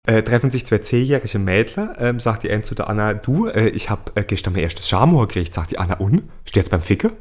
Werbespot - Toyota Running